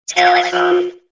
Telephone ringtone download